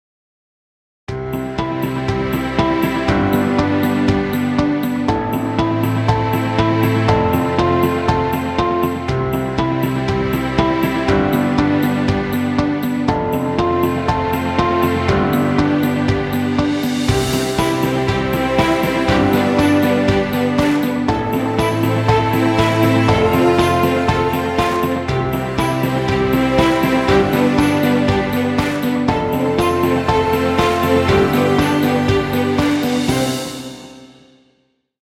motivational corporate track with positive mood.